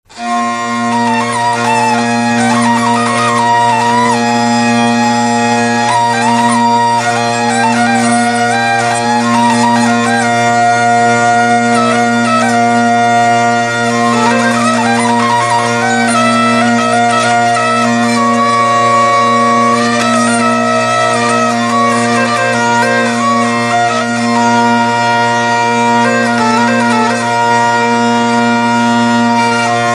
Dallampélda: Hangszeres felvétel
Alföld - Pest-Pilis-Solt-Kiskun vm. - Tiszaújfalu
tekerő Műfaj: Hallgató nóta Gyűjtő